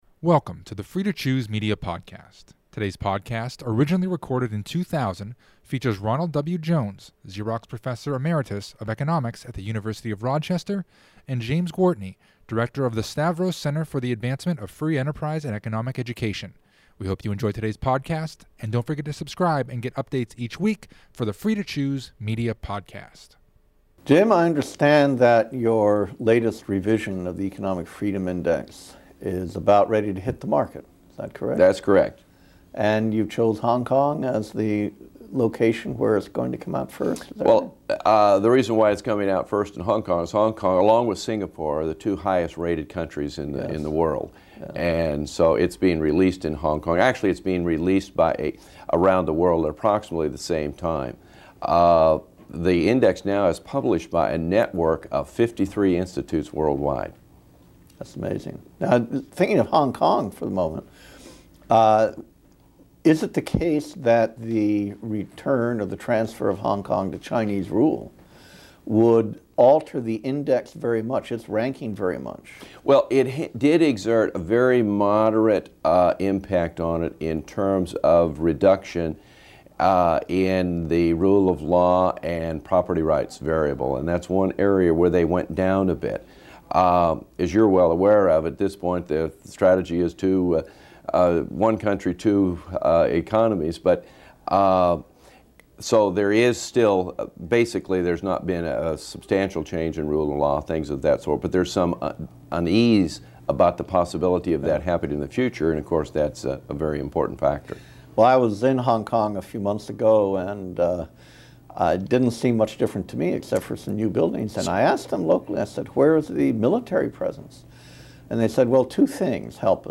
have a lengthy conversation about the concepts of economic freedom and prosperity. Does wealth equal economic freedom?